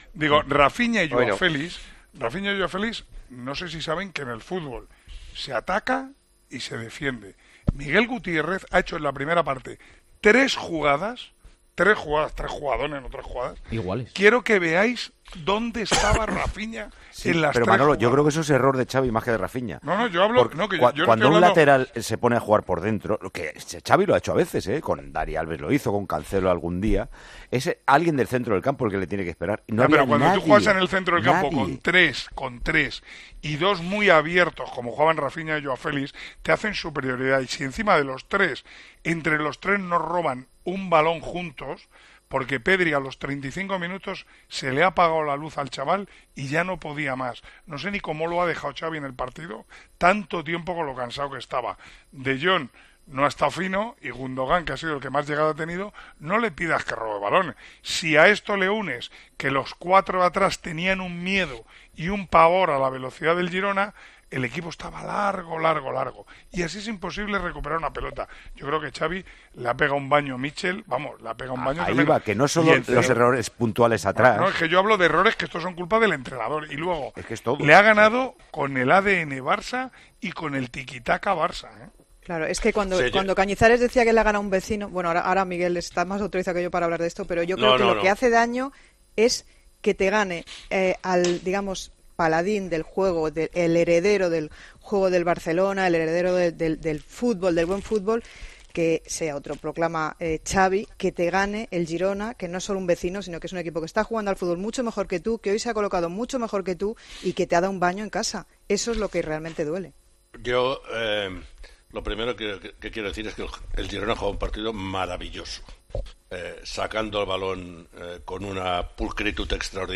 El narrador de Tiempo de Juego dejó clara la falta de implicación defensiva de dos atacantes del conjunto azulgrana contra el Girona.